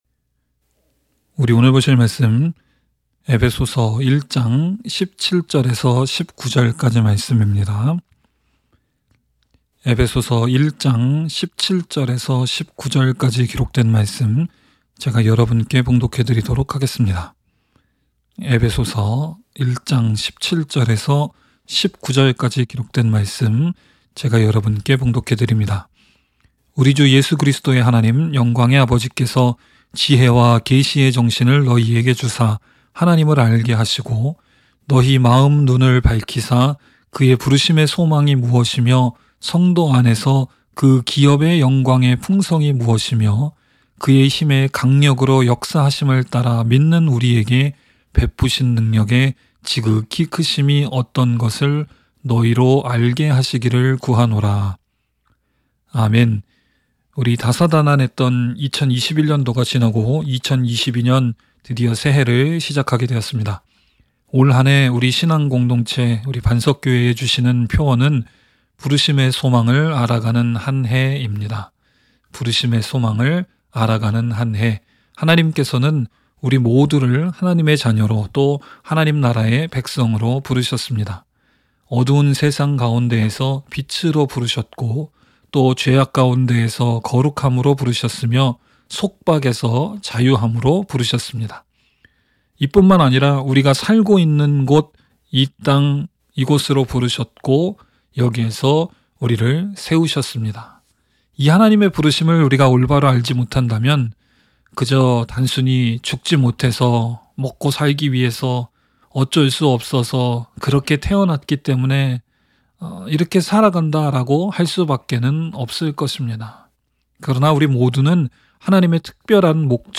by admin-new | Jan 3, 2022 | 설교 | 0 comments